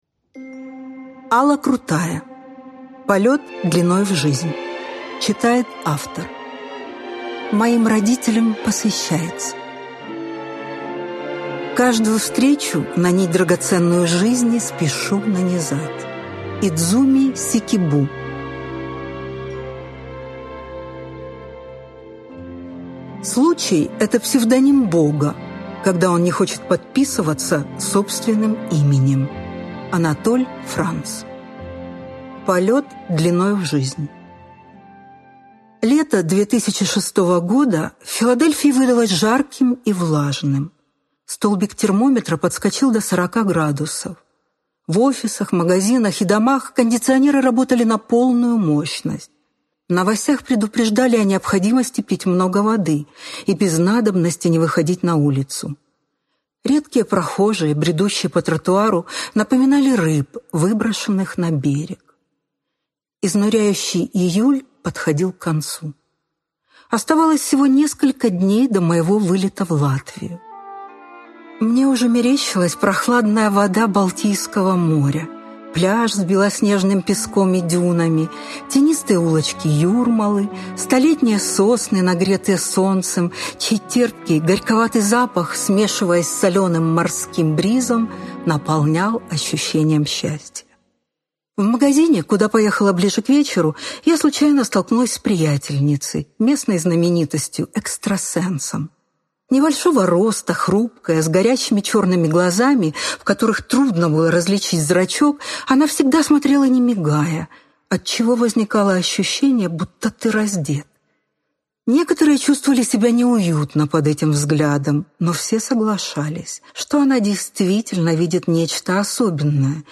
Аудиокнига Полет длиною в жизнь | Библиотека аудиокниг